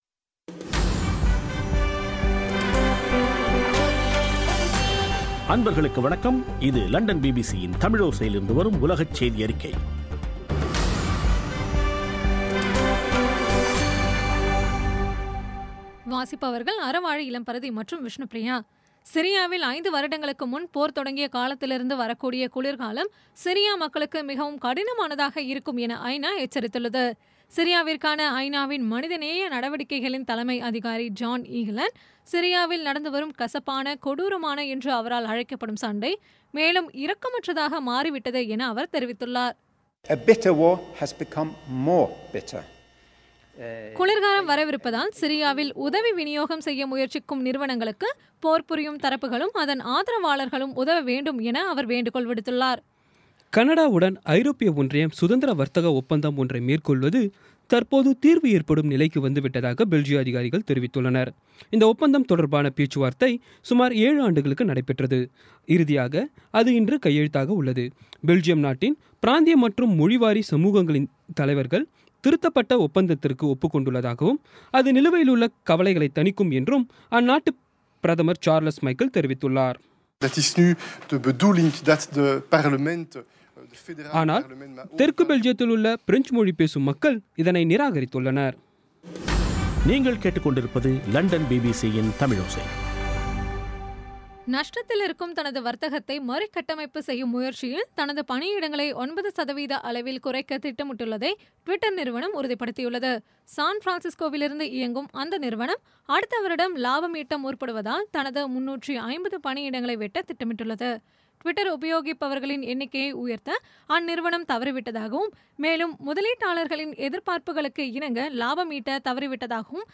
இன்றைய (அக்டோபர் 27ம் தேதி ) பிபிசி தமிழோசை செய்தியறிக்கை